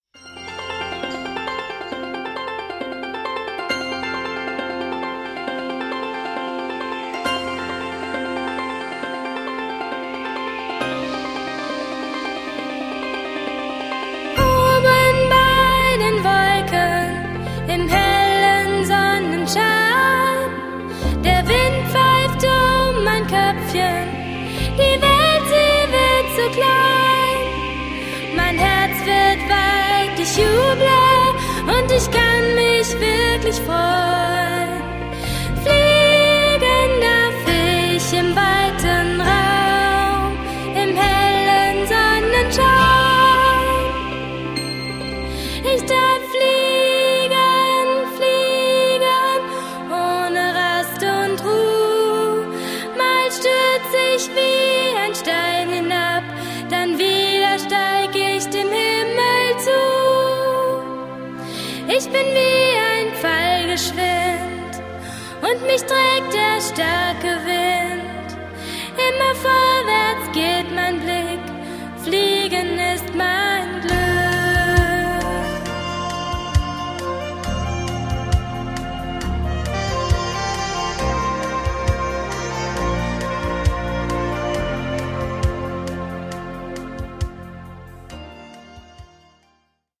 Ein Musical für Kinder